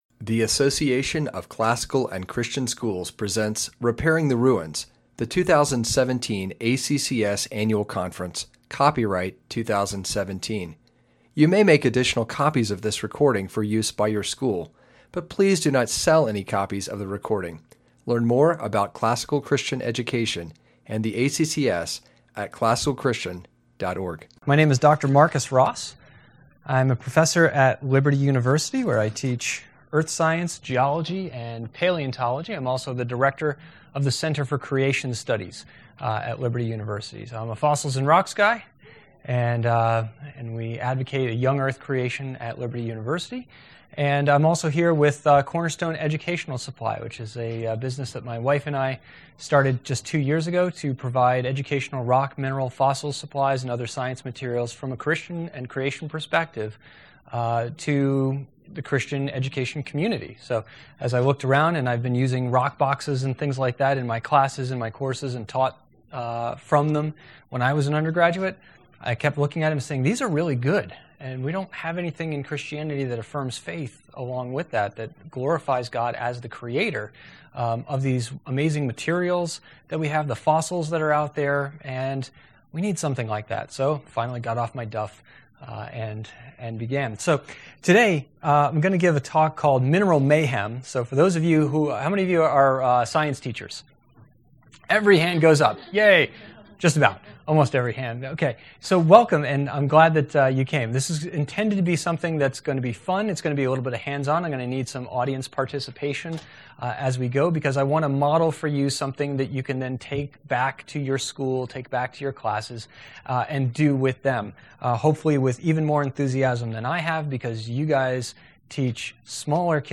2017 Workshop Talk | 0:51:29 | All Grade Levels, Science